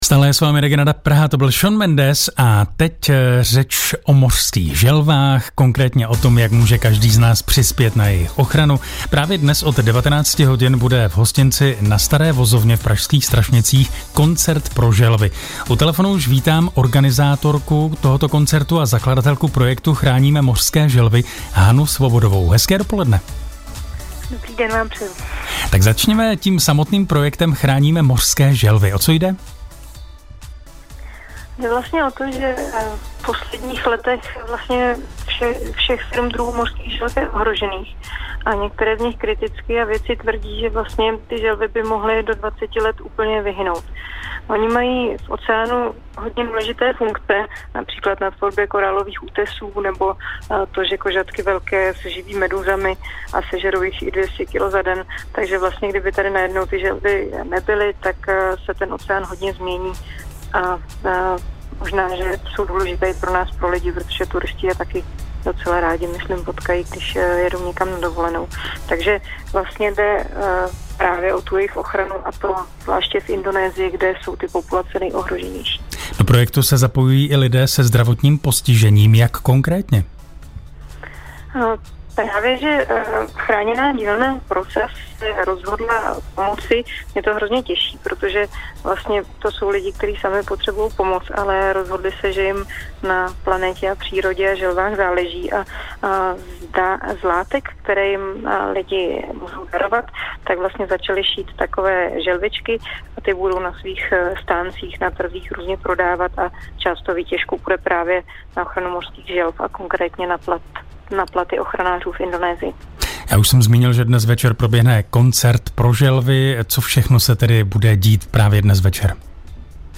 Rozhovor v Českém rozhlase – o koncertě pro želvy, ale také o tom, proč a jak želvám pomáhá Chráněná dílna PROSAZ